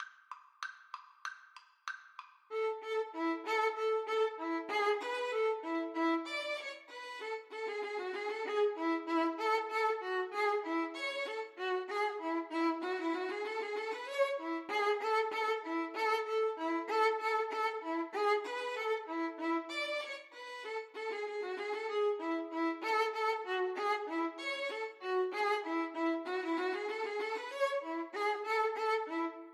Violin 1Violin 2
A major (Sounding Pitch) (View more A major Music for Violin Duet )
2/4 (View more 2/4 Music)
Violin Duet  (View more Intermediate Violin Duet Music)
Traditional (View more Traditional Violin Duet Music)
world (View more world Violin Duet Music)